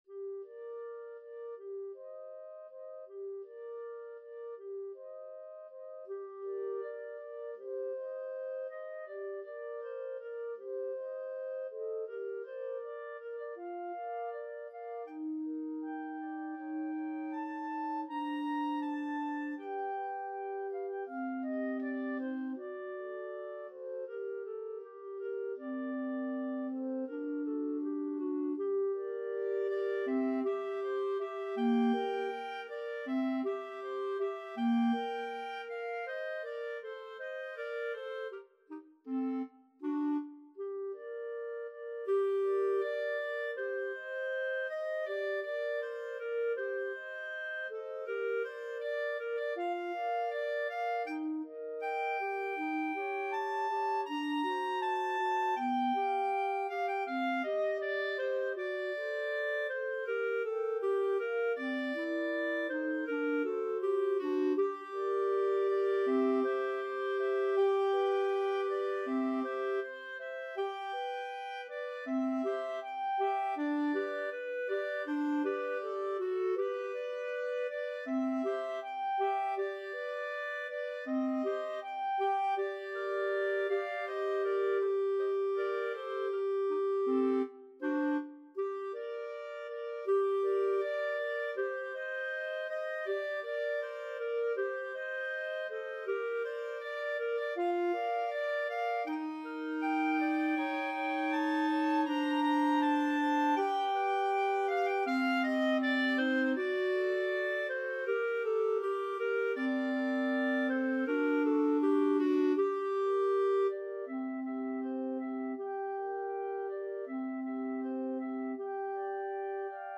~ = 100 Andante
2/4 (View more 2/4 Music)
Classical (View more Classical Clarinet Trio Music)